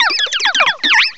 cry_not_steenee.aif